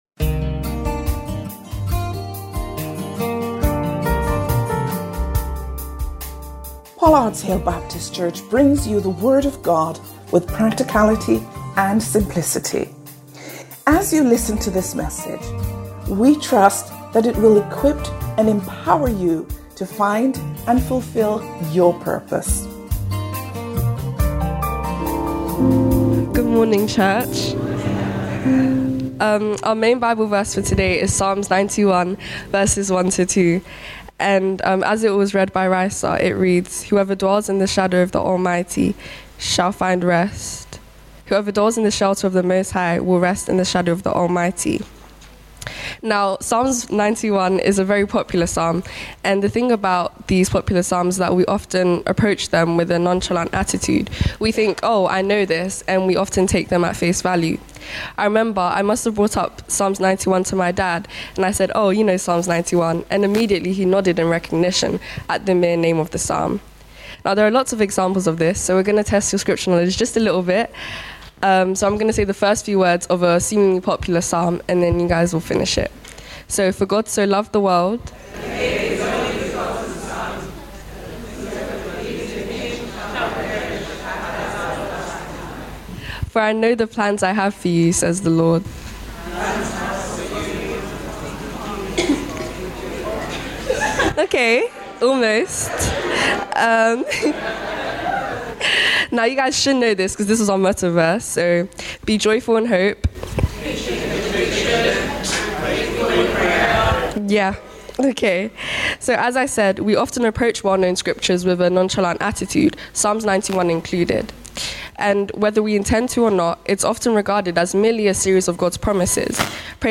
Sermons – Pollards Hill Baptist Church